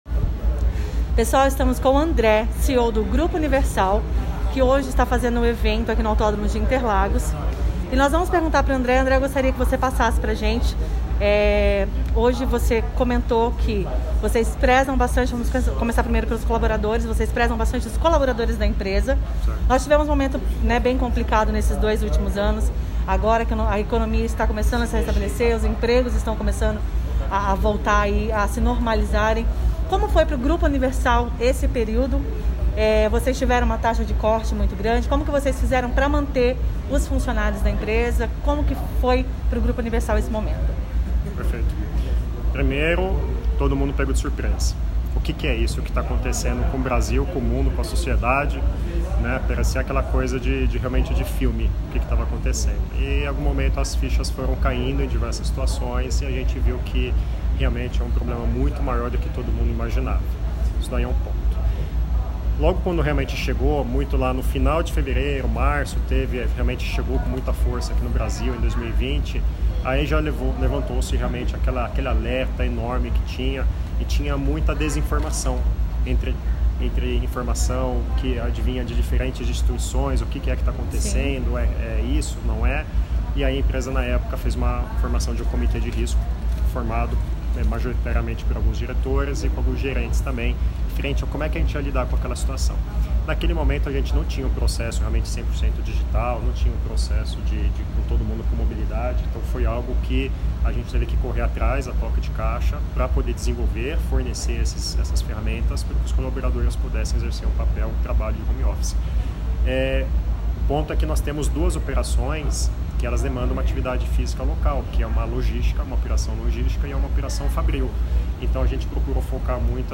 No dia de Fevereiro, o Grupo Universal Automotive comemorou seu aniversário de 45 anos com uma super festa para seus parceiros e convidados no Autódromo de Interlagos, foi realizado o Motor Experience 2022.
em entrevista exclusiva ao JW News